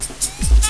FXLOOP 01 -L.wav